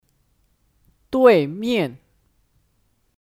对面 (Duìmiàn 对面)